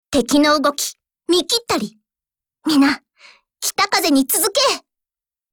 Cv-39903_warcry.mp3